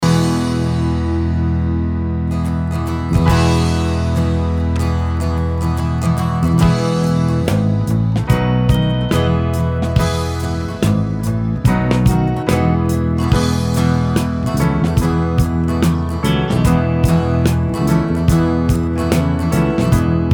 Listen to a sample of this instrumental song.
Downloadable Instrumental Track